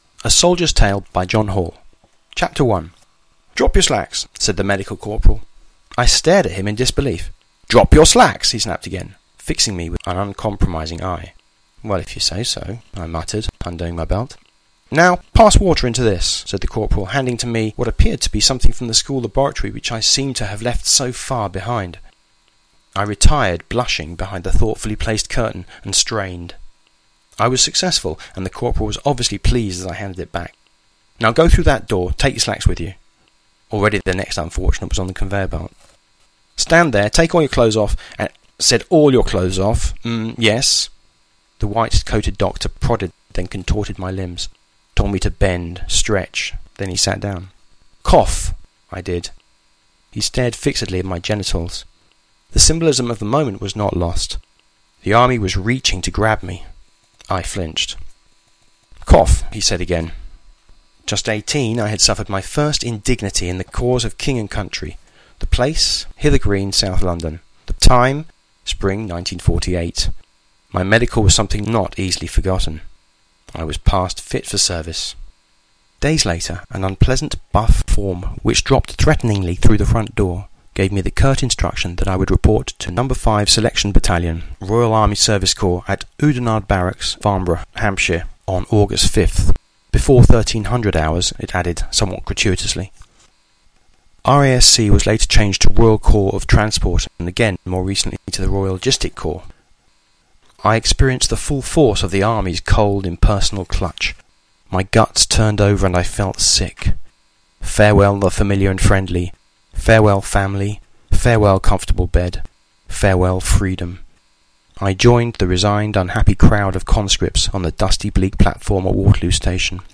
An audio recording of this chapter is available here: